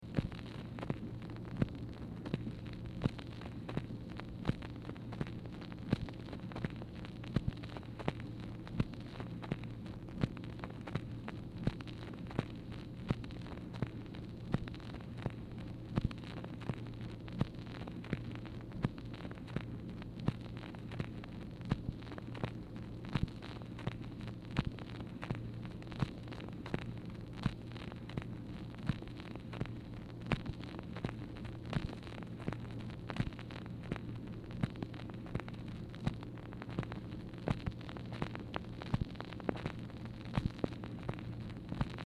MACHINE NOISE
Format Dictation belt